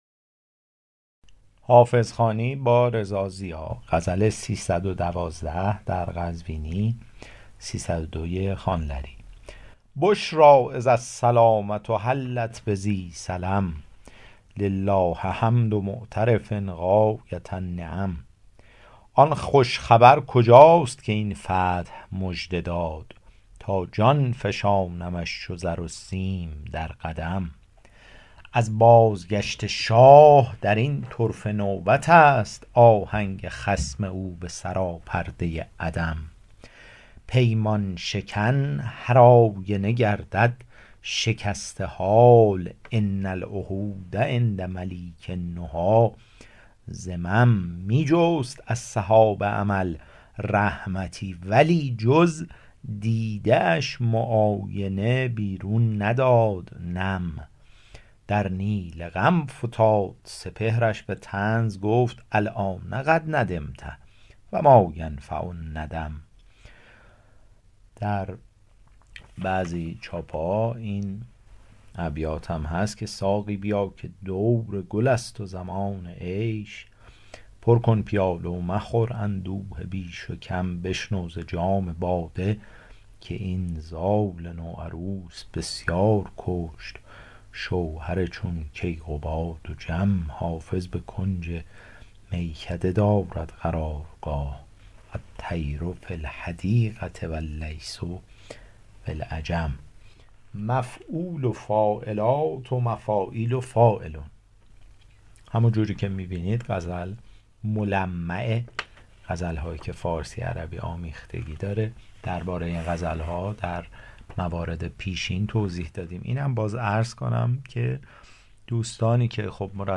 شرح صوتی غزل شمارهٔ ۳۱۲